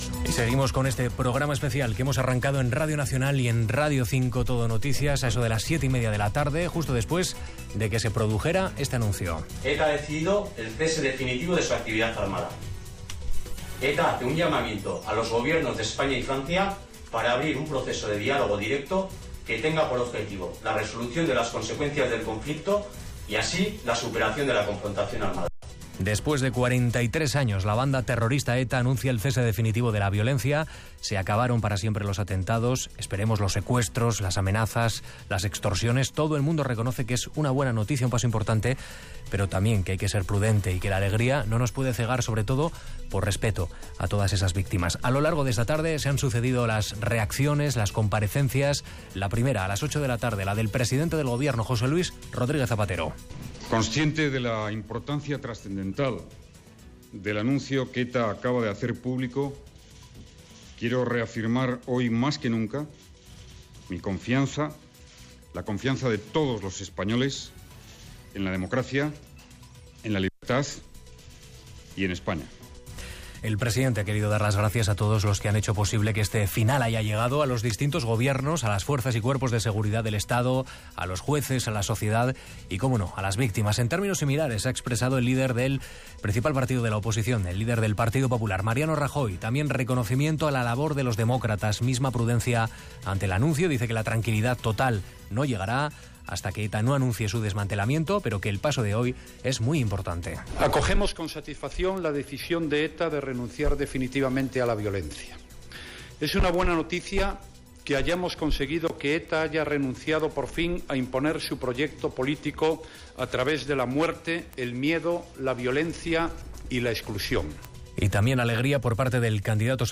Programa especial dedicat a l'anunci d'ETA que posa fi a la seva activitat armada. Amb declaracions del president del govern espanyol José Luis Rodríguez Zapatero, el líder del Partido Popular Mariano Rajoy, etc.
Informatiu